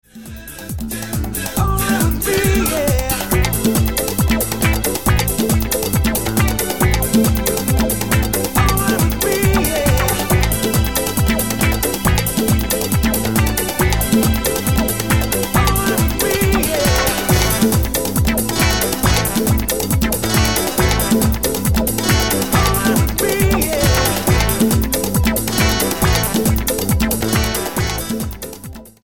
STYLE: Hip-Hop
There's some mad trombone in there as well.